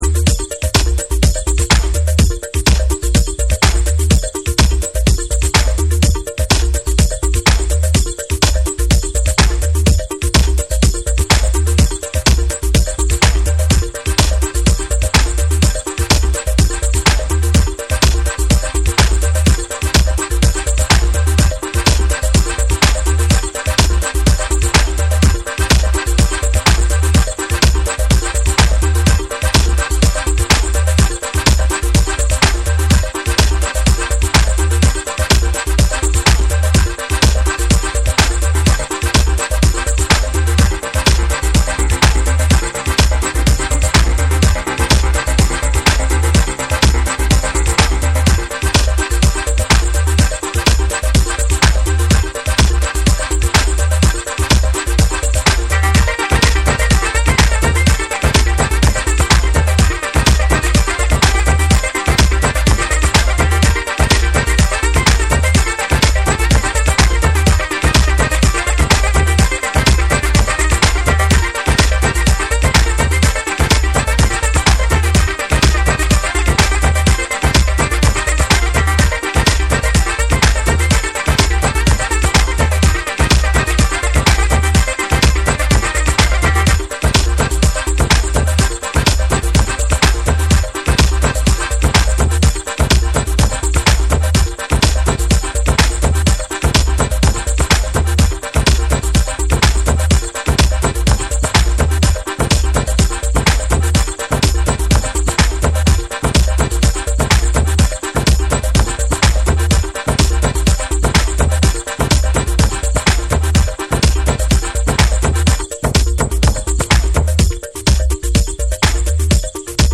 ケルト音楽のメロディと西アフリカ音楽のリズムをクラブ的に融合したグループ
TECHNO & HOUSE / ORGANIC GROOVE